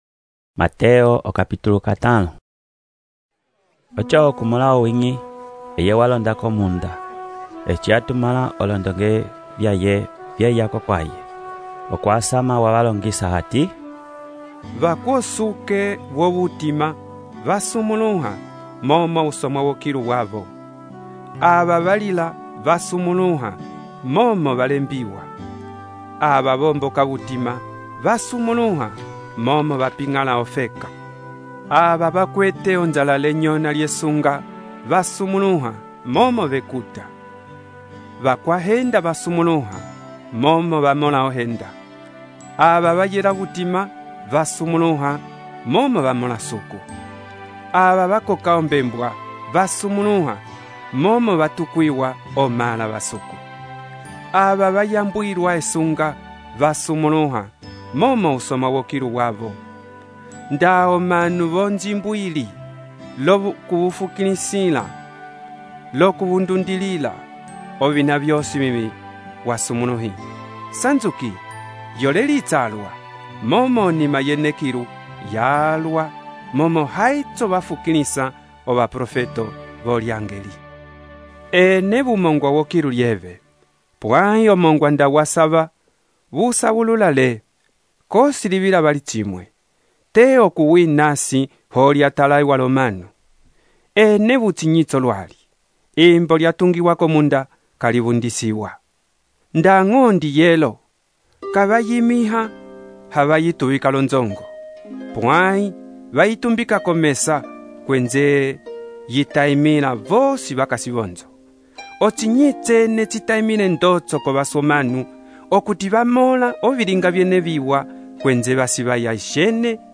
texto e narração , Mateus, capítulo 5